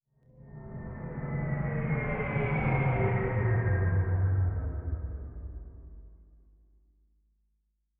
Distant Ship Pass By 1_2.wav